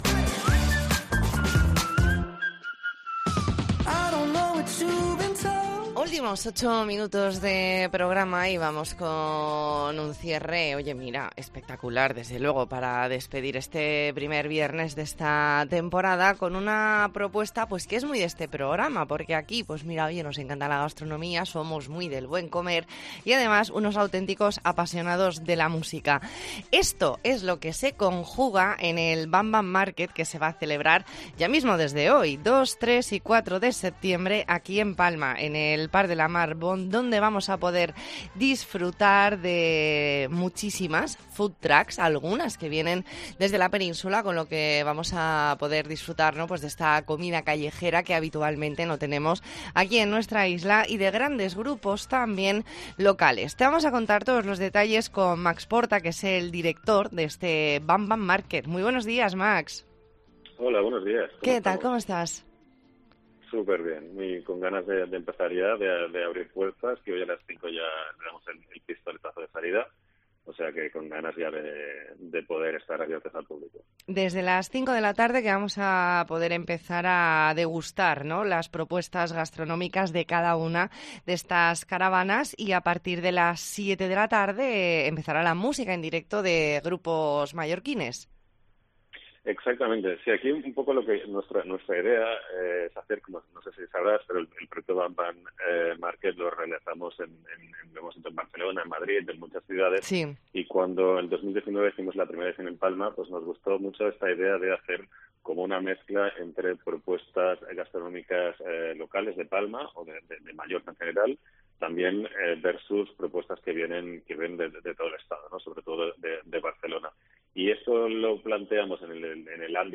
ntrevista en La Mañana en COPE Más Mallorca, viernes 2 de septiembre de 2022.